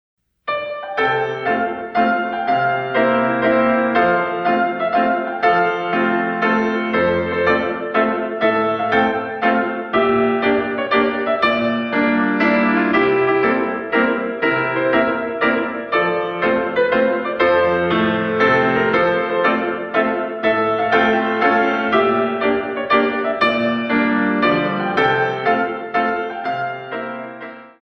In 3
128 Counts